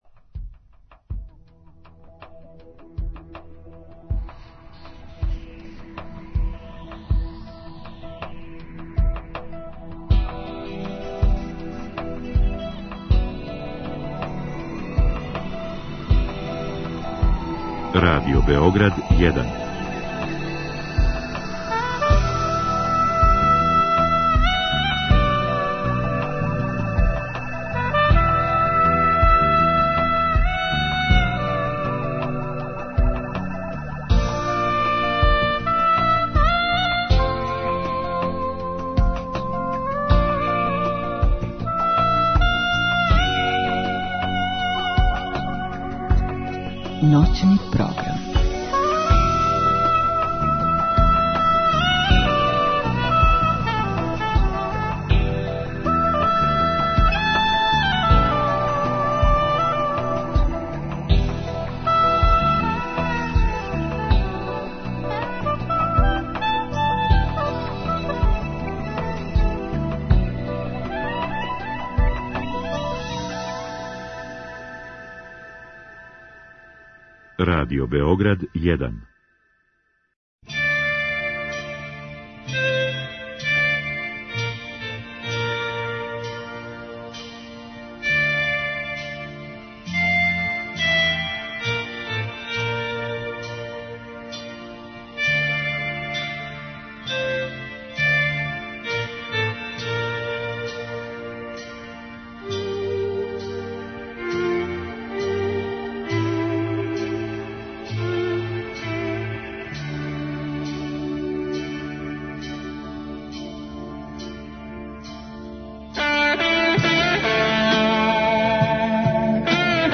Гост: Срђан Марјановић, кантаутор